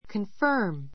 confirm kənfə́ː r m コン ふァ ～ ム